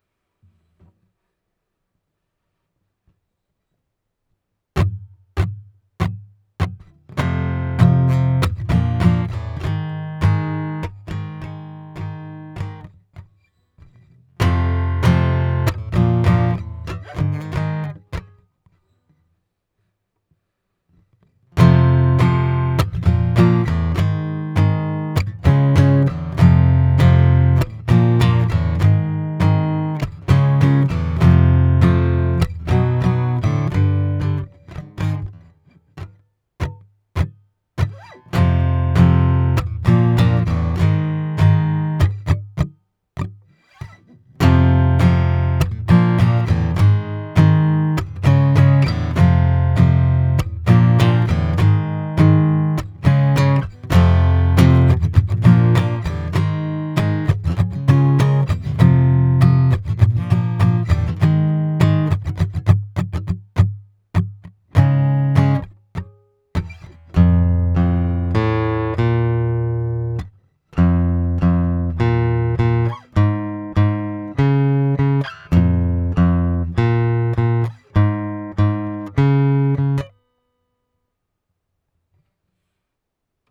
smell-guitar1.wav